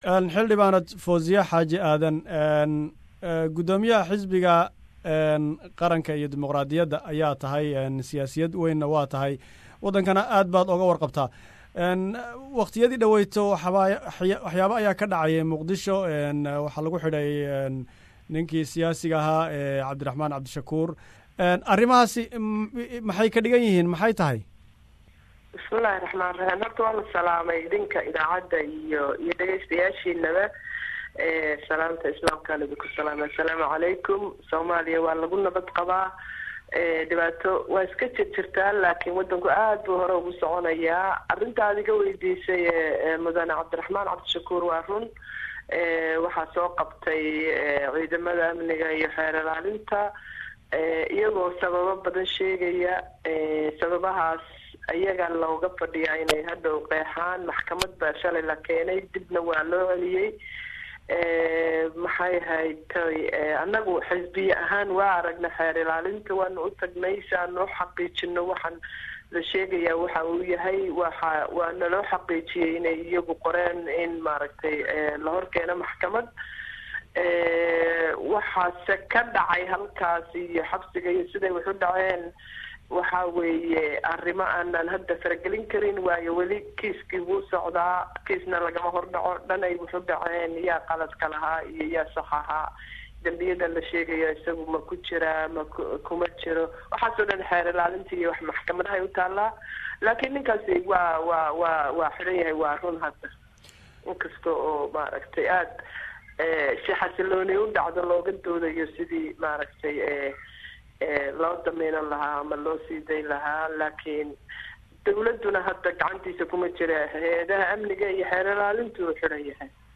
Waraysi aan Xildhibaanad Fozia Yusuf Xaaji Aden, ka waraysanay xidhitaankii siyaasi Cabdiraxmaan Cabdishakuur ee Muqdisho.